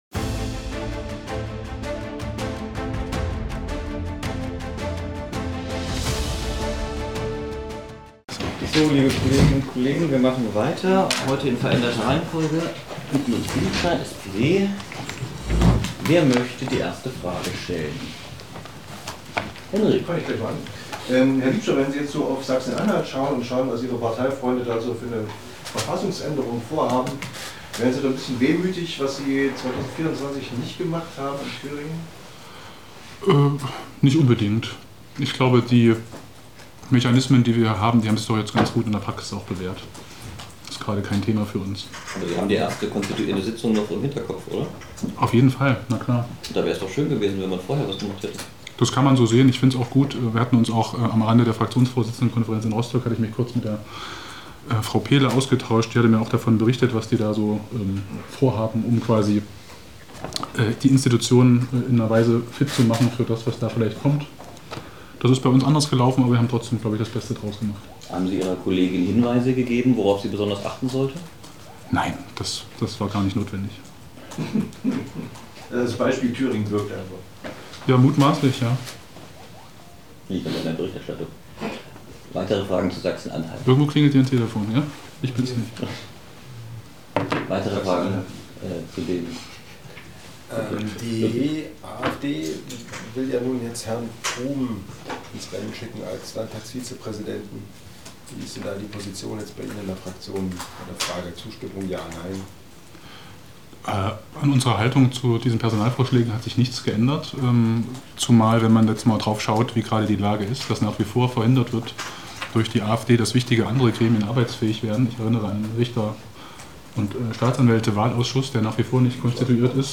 Ein Gast � ein Moderator. In unserer SALVE-Talkshow �IM GESPR�CH� widmen wir uns ausf�hrlich spannenden Themen aus Wirtschaft, Politik, Kunst und Gesellschaft.